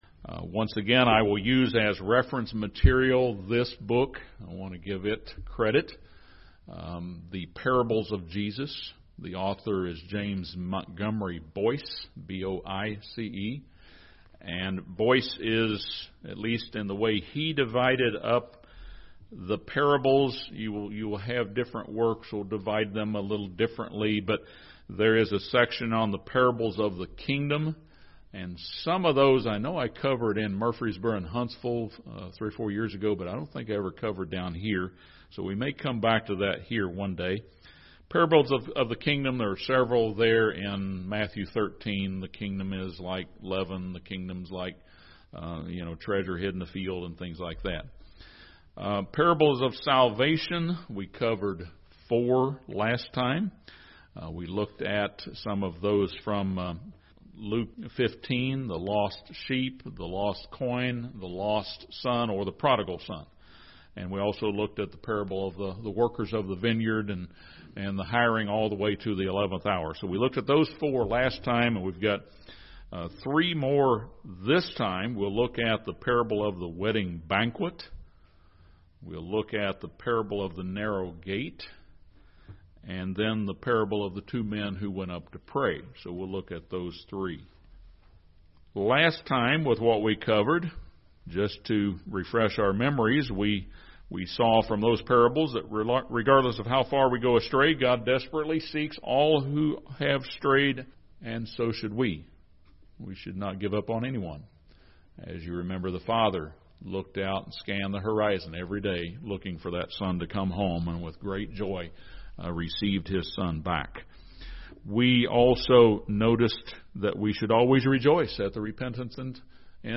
This sermon expounds the meaning of the parables of the wedding banquet, the narrow gate to salvation, and the two men who went up to pray. Each stresses that salvation is a gift of God.